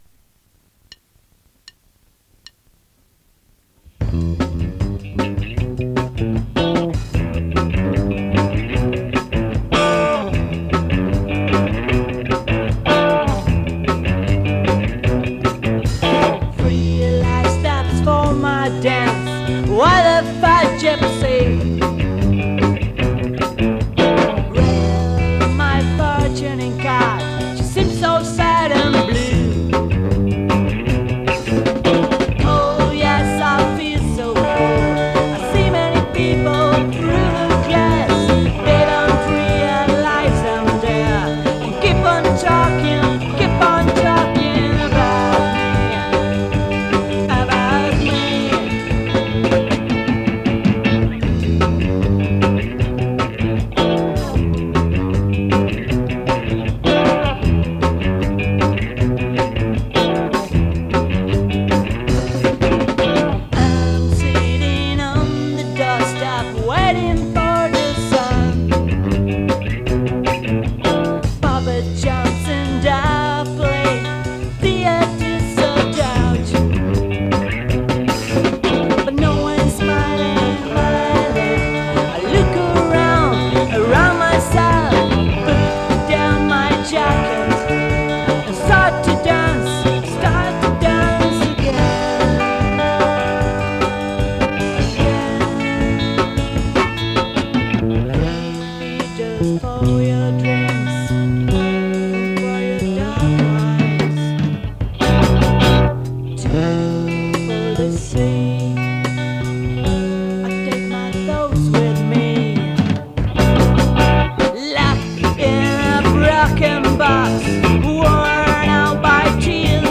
basso